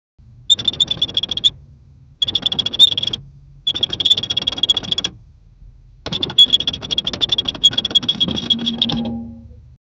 high-quality-sound-effect-khqxwde4.wav